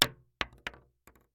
Bullet Shell Sounds
shotgun_wood_6.ogg